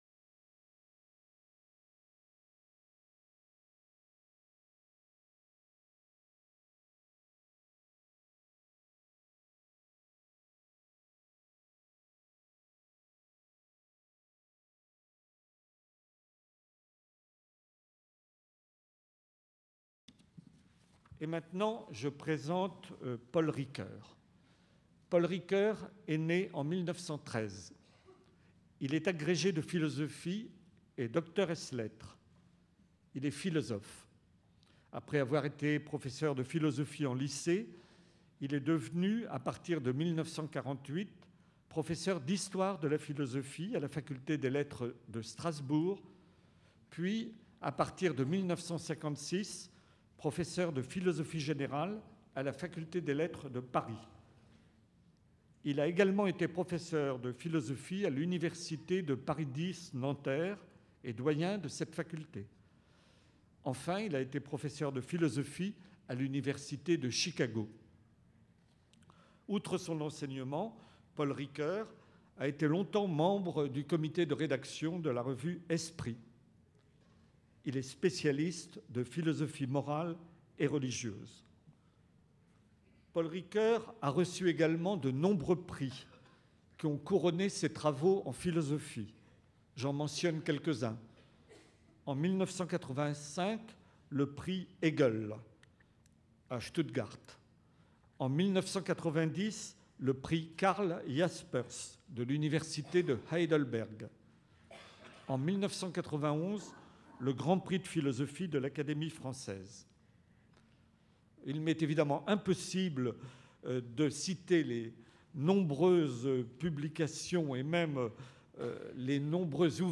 Conférence de Paul Ricoeur : "La croyance religieuse"